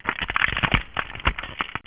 freeze.wav